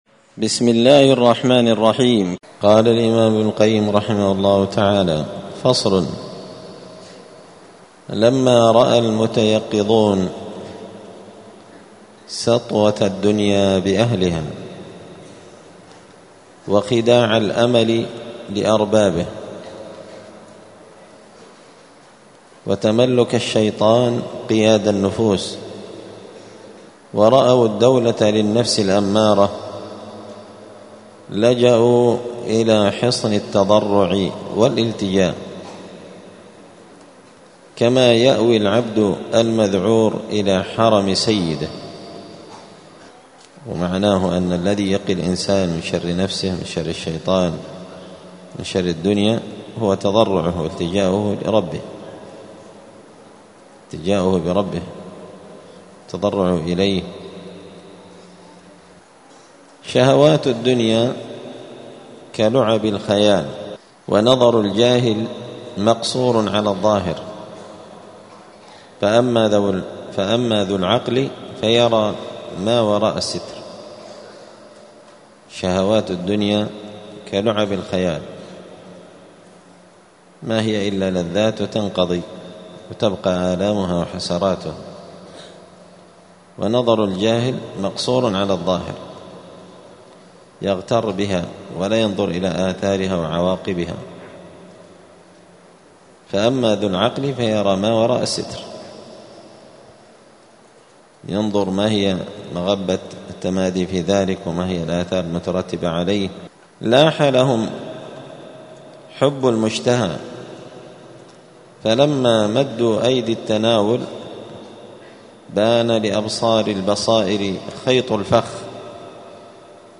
الجمعة 13 جمادى الأولى 1446 هــــ | الدروس، دروس الآداب، كتاب الفوائد للإمام ابن القيم رحمه الله | شارك بتعليقك | 48 المشاهدات